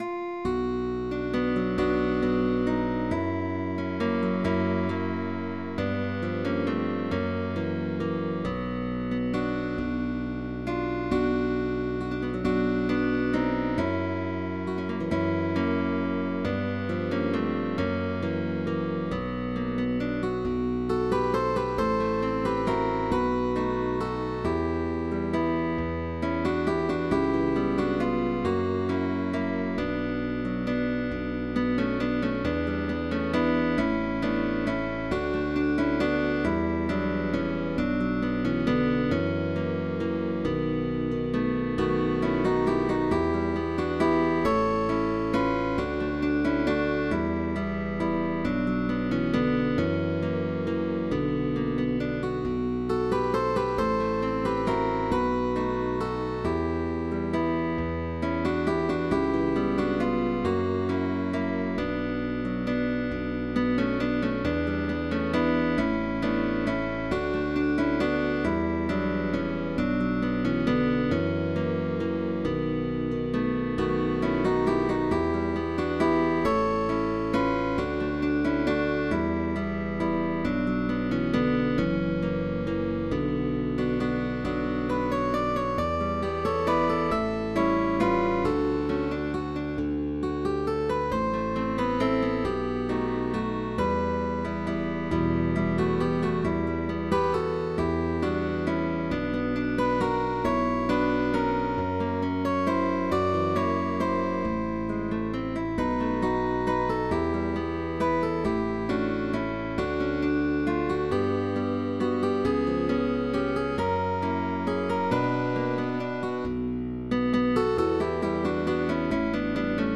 With optional bass, also valid for guitar orchestra